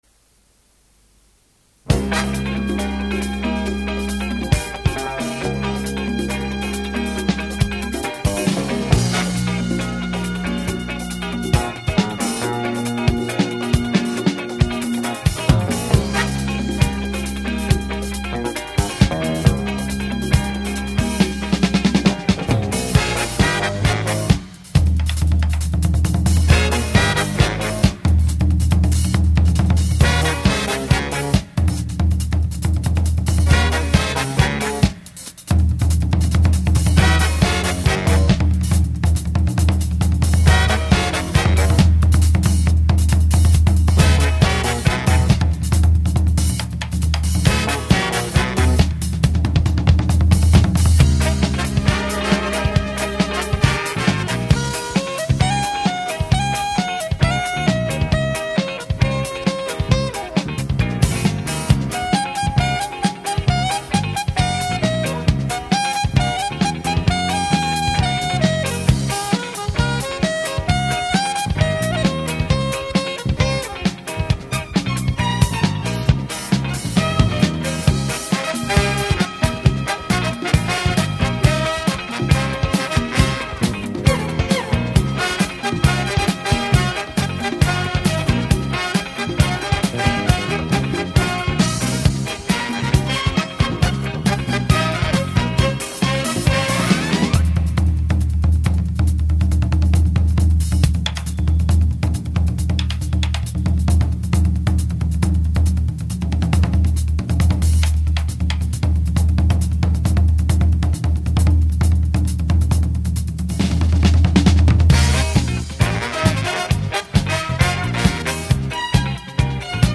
1.SHARP GF-777Z双卡双声道四喇叭收录机试机音乐
2.TOSHIBA RT- S 983双卡双声道四喇叭收录机试机音乐